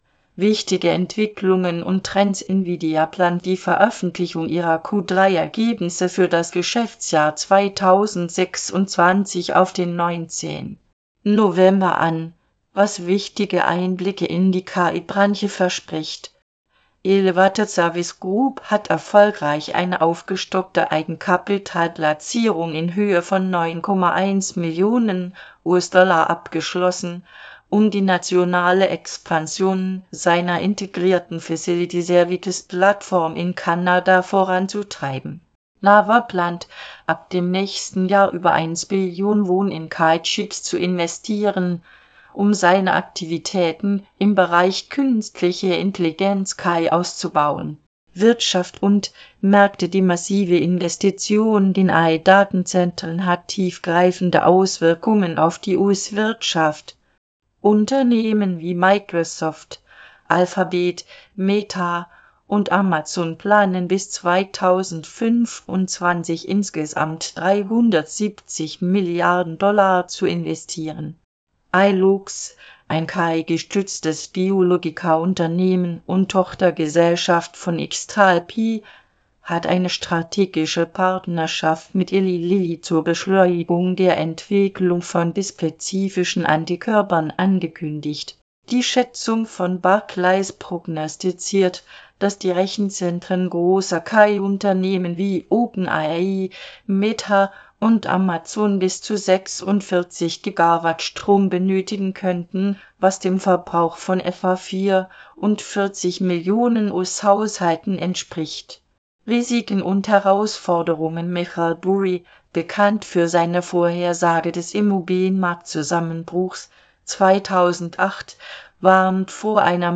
Vorlesen (MP3)